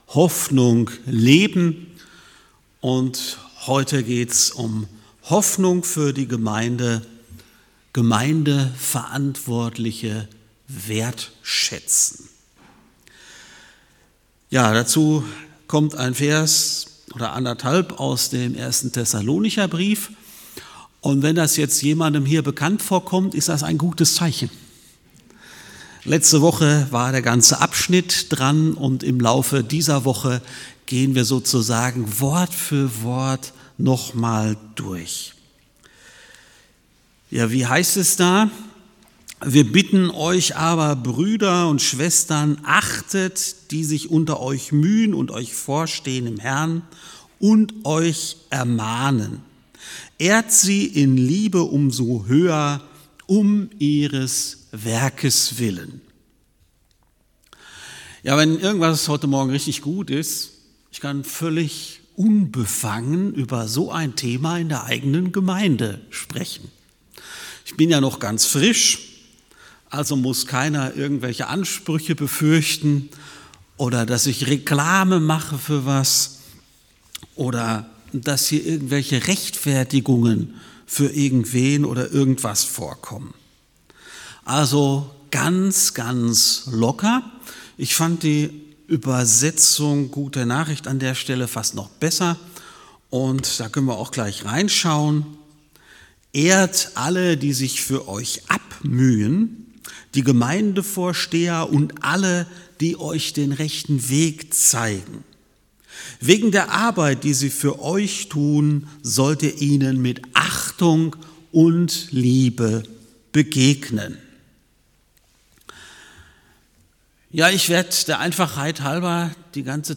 Predigt Podcast